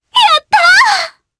Selene-Vox_Happy4_jp_b.wav